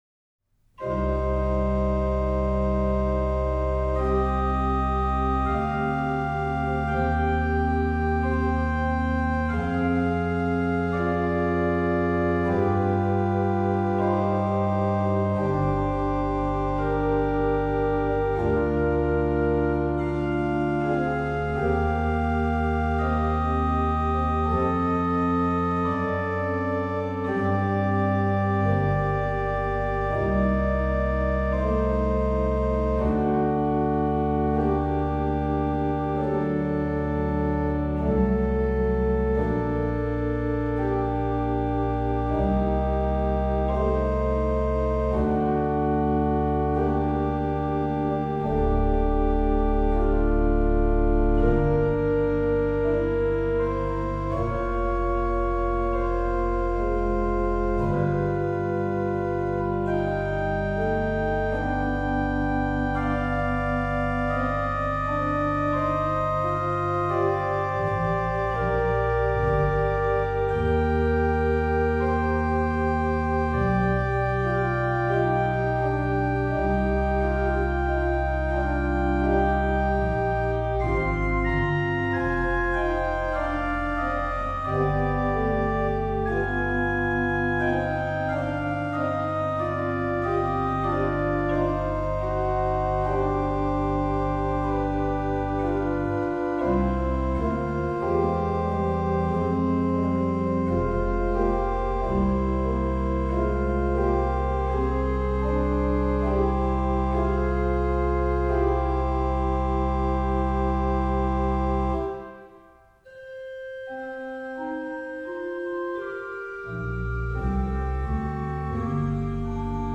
Ped: Sub16, Qnt16, Por8
rh: BW: Ged8, Nacht8